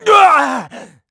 Riheet-Vox_Damage_kr_05.wav